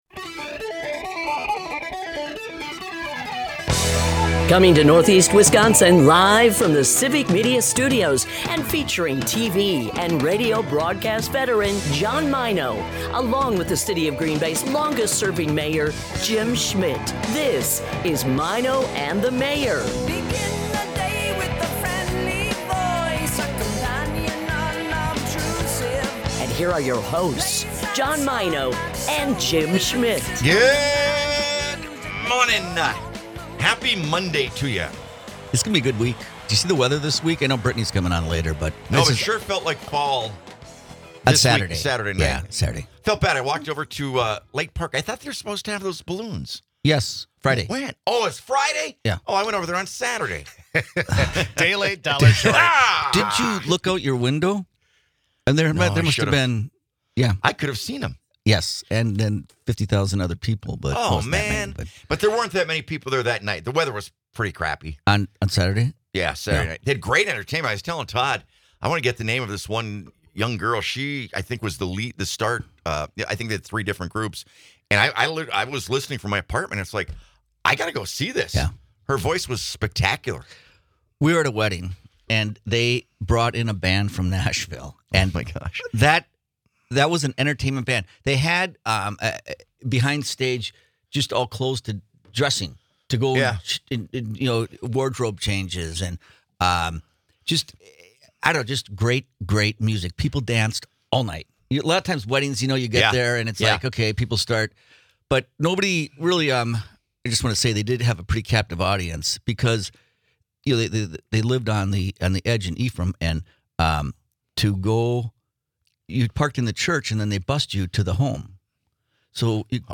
Broadcasts live 6 - 9am in Oshkosh, Appleton, Green Bay and surrounding areas.